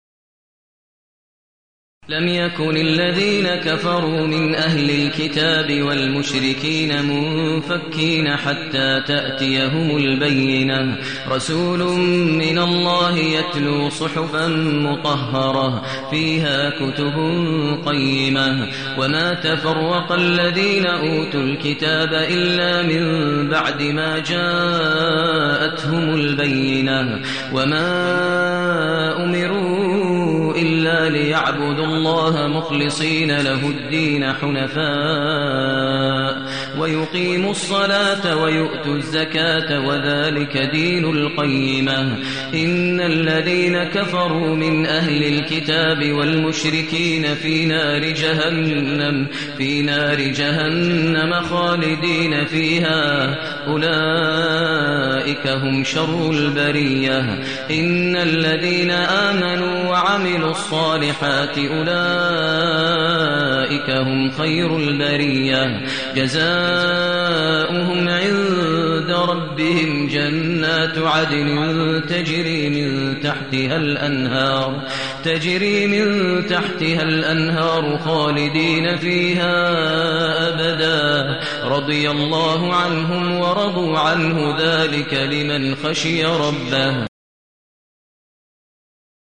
المكان: المسجد الحرام الشيخ: فضيلة الشيخ ماهر المعيقلي فضيلة الشيخ ماهر المعيقلي البينة